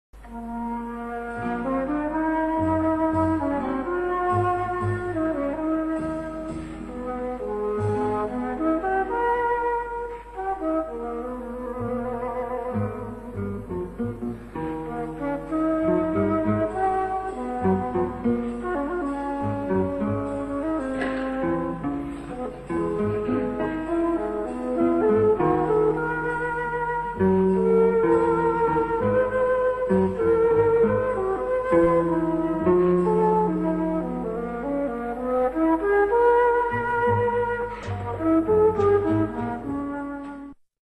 für Solo-Flöte und kleines Orchester bzw. Klavier